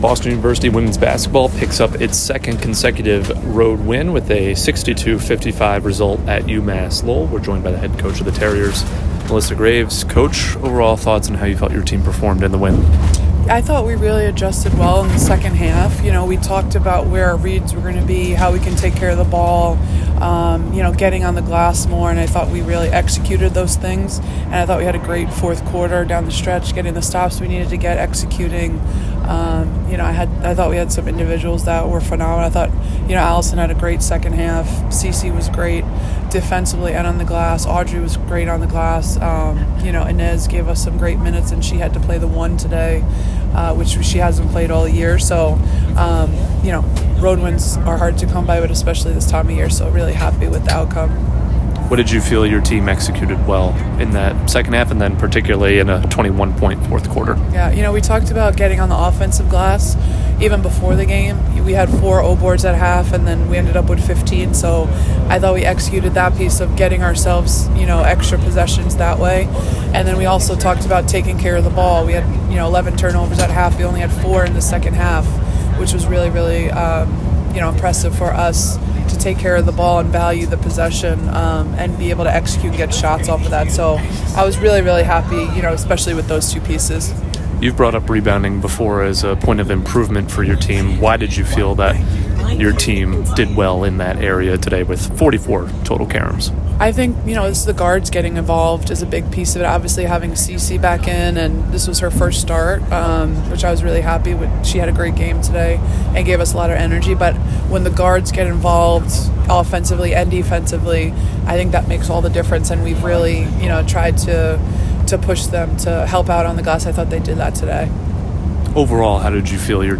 WBB_UML_Postgame.mp3